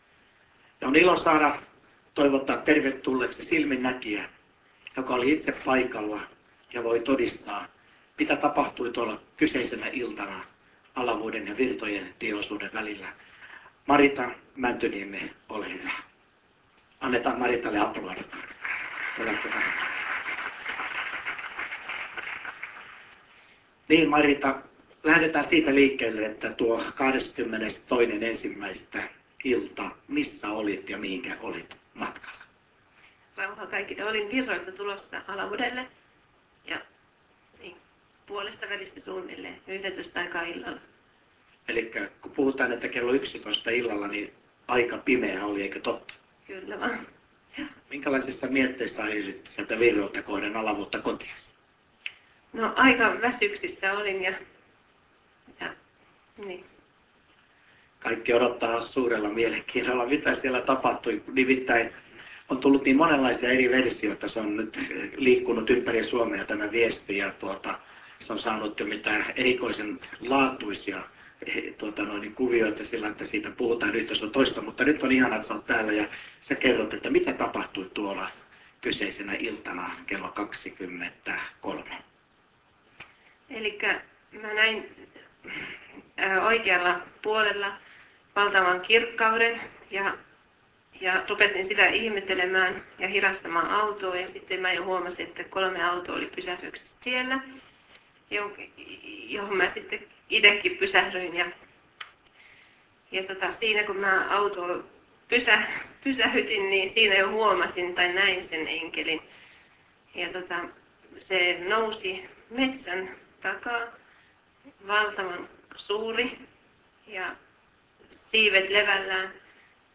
Silminnäkijä